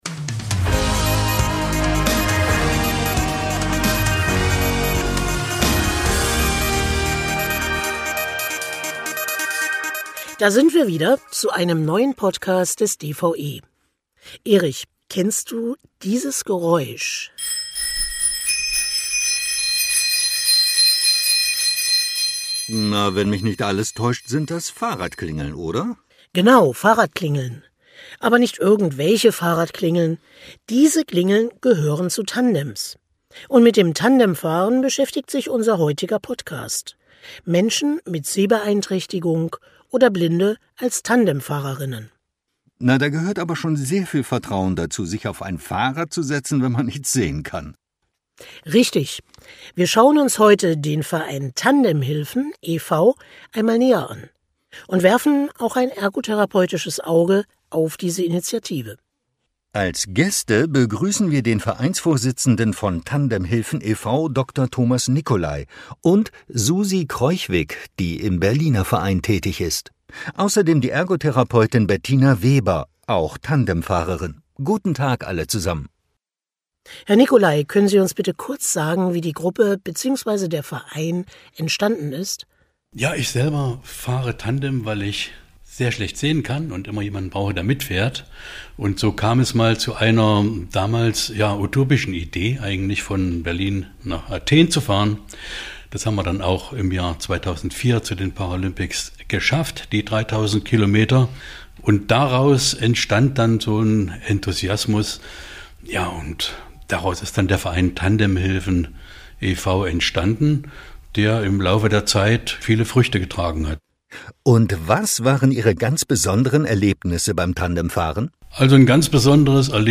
Beschreibung vor 7 Monaten Eine Fahrradklingel klingt ganz normal – doch diese gehört zu einem Tandem! In dieser Folge schauen wir auf ein besonderes Projekt: Menschen mit Sehbeeinträchtigung oder Blindheit als Tandemfahrer:innen. Wir stellen den Verein Tandem-Hilfen e.V. vor und beleuchten die Initiative auch aus ergotherapeutischer Sicht.